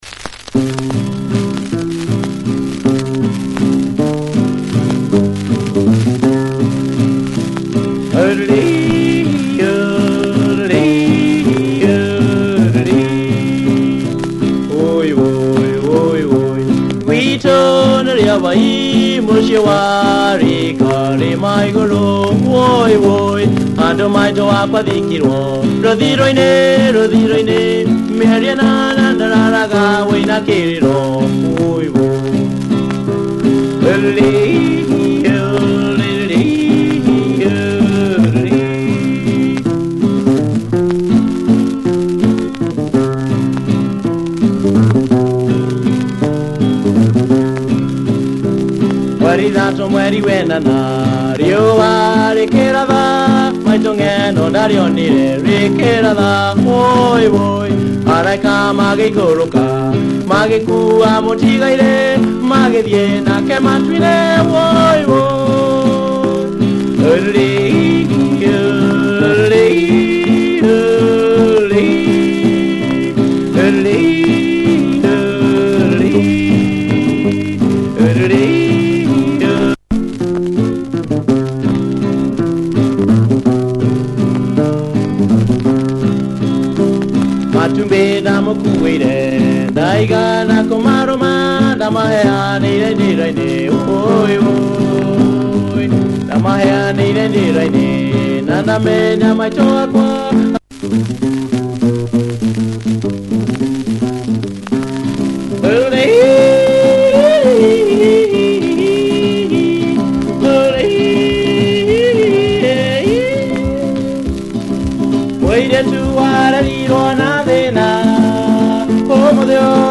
Early Kikuyu with Hillbilly Hank Wiliams influence!
Worn disc check audio! https